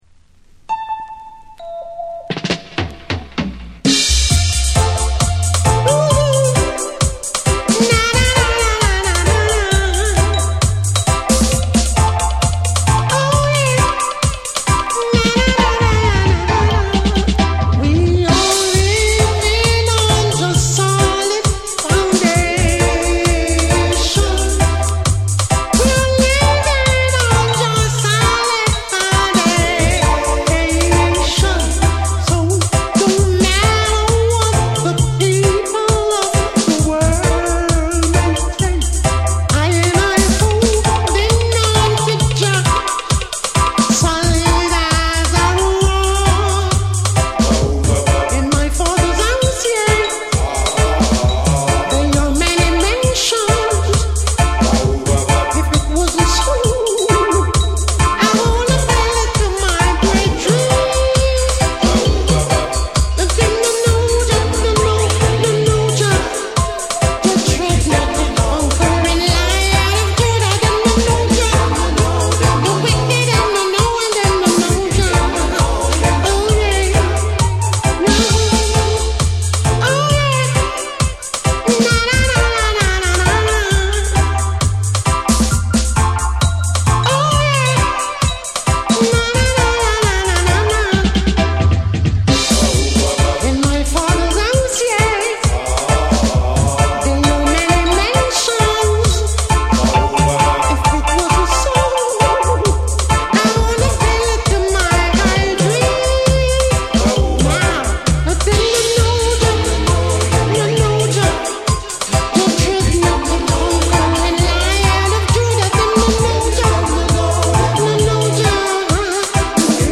名門ルーツ・ヴォーカル・グループ
重厚なベースと空間処理が光る、ルーツ〜ダブ好きに外せない1枚。